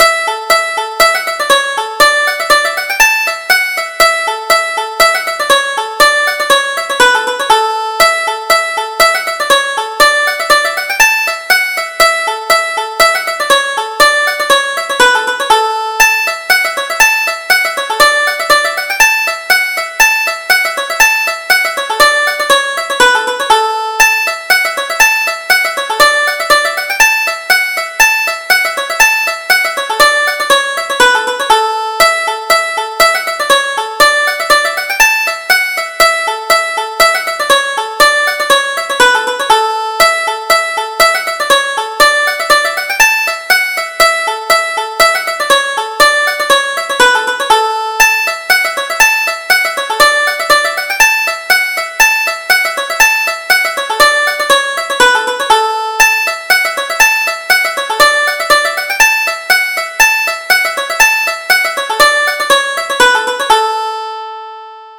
Ger the Rigger: Polka
Irish Traditional Polkas